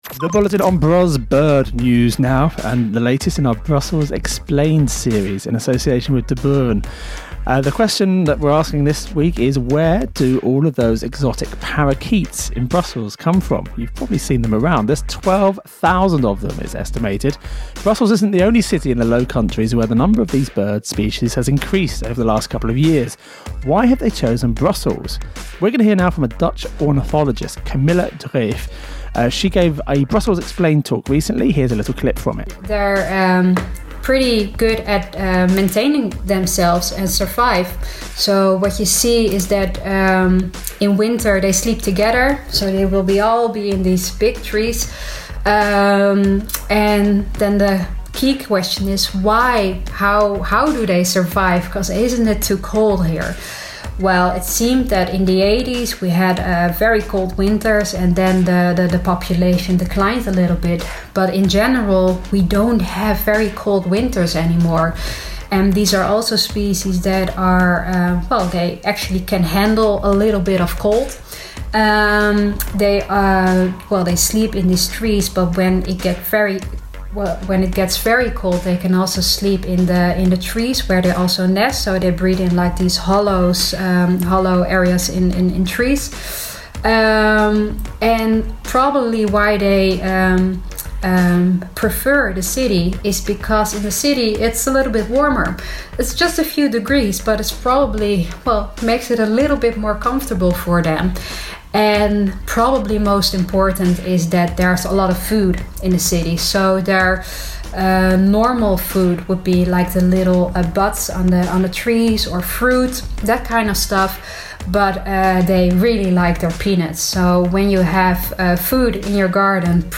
gives a presentation on liveability in Brussels, with a focus on the improvement of air quality and the promotion of soft mobility.